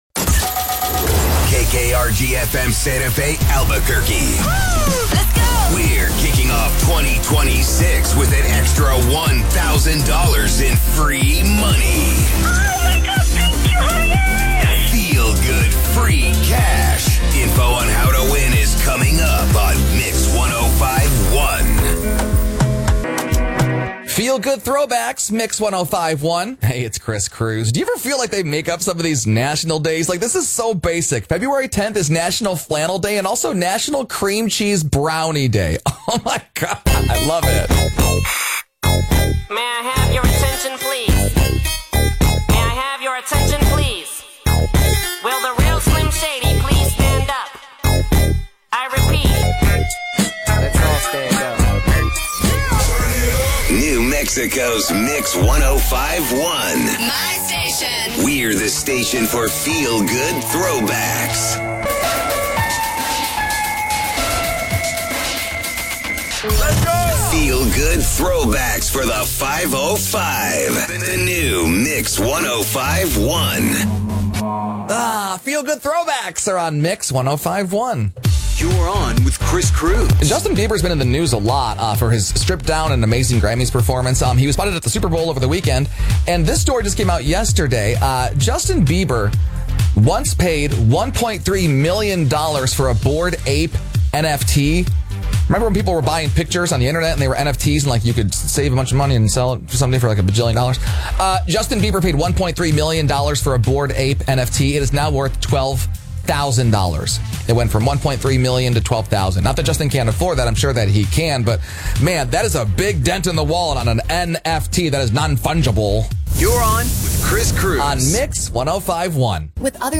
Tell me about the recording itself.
with your locally programmed music (as heard on KKRG/Albuquerque)